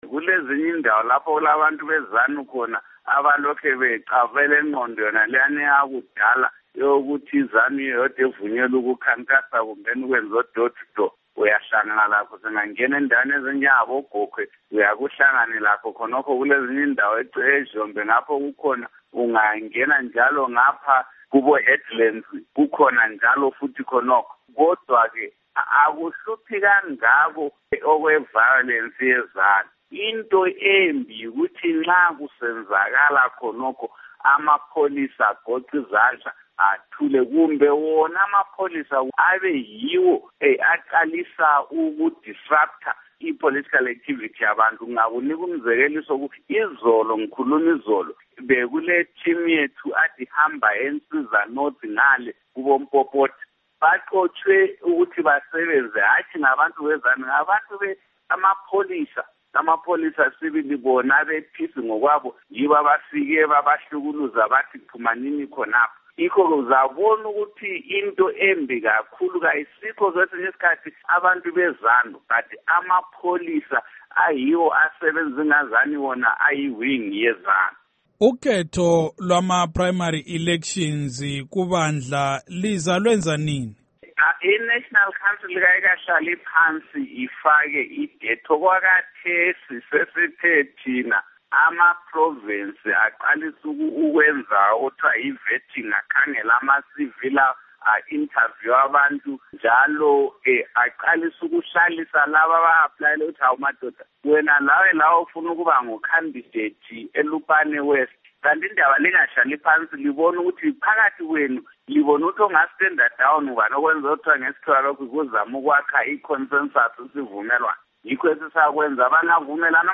Ingxoxo LoMnu Welshman Ncube